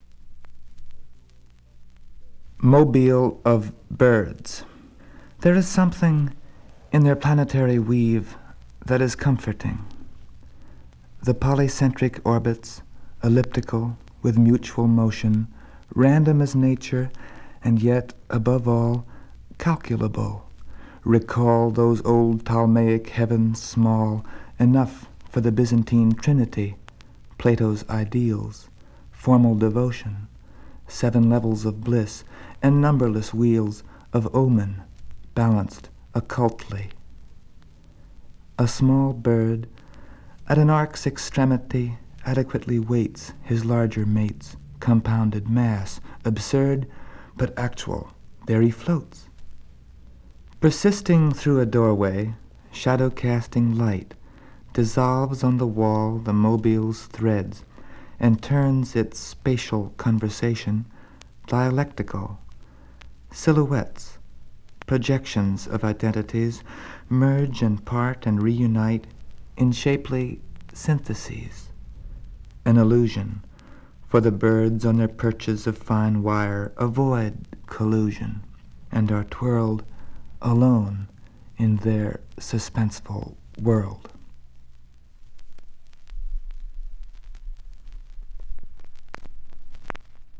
"Poem: "Mobile Of Birds"" from Reading From His Works by John Updike.